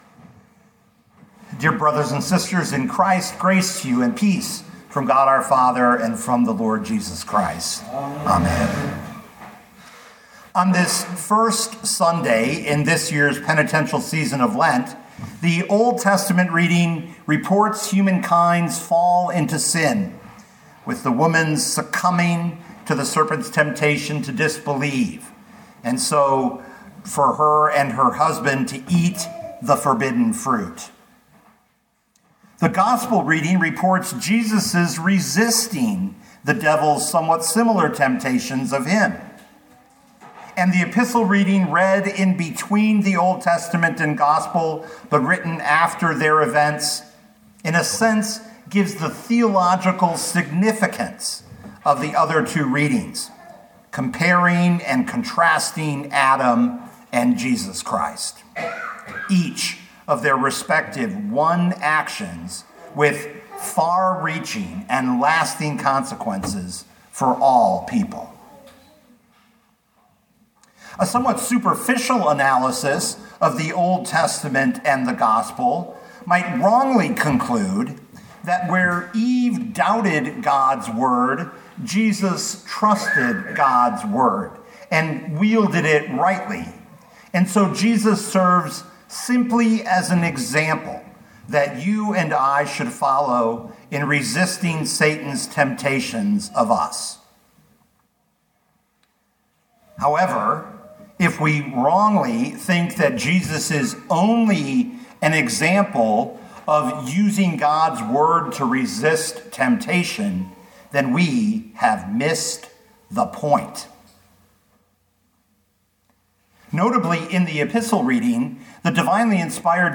2026 Matthew 4:1-11 Listen to the sermon with the player below, or, download the audio.